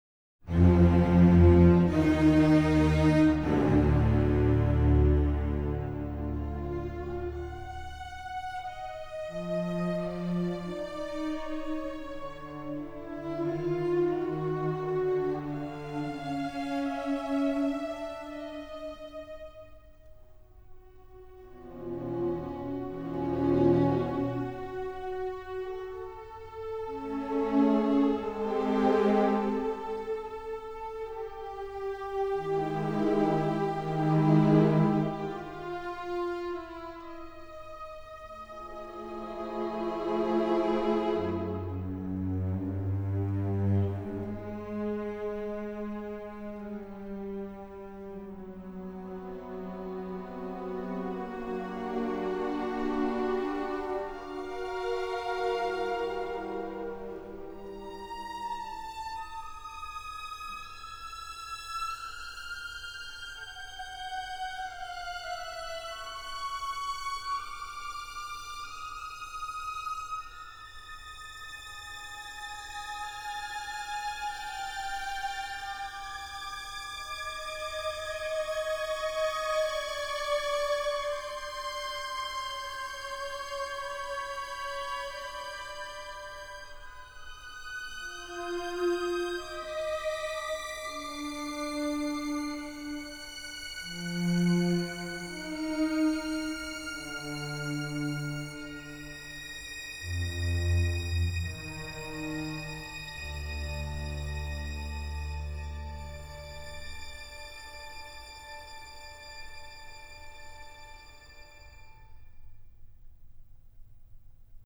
emotional, yearning, sighing strings